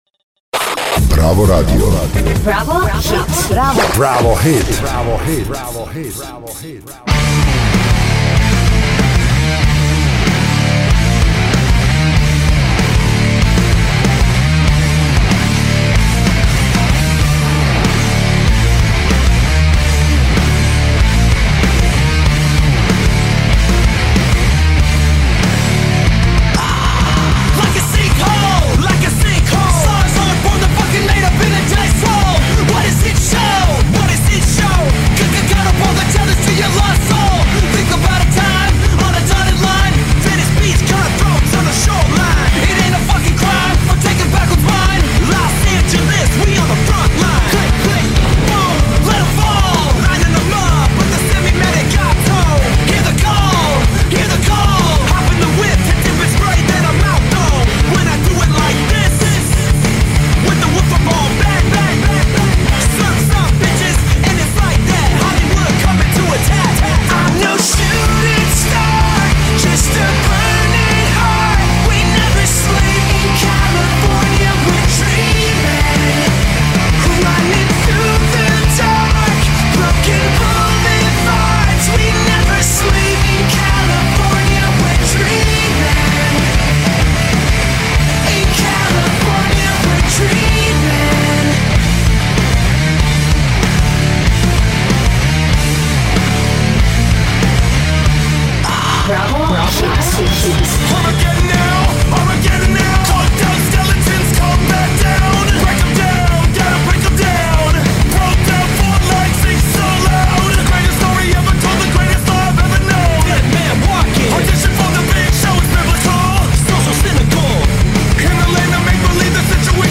rap rock band